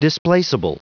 Prononciation du mot displaceable en anglais (fichier audio)
Prononciation du mot : displaceable